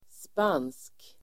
Uttal: [span:sk]